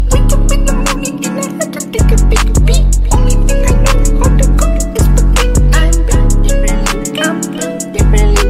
dimaond sound sound effects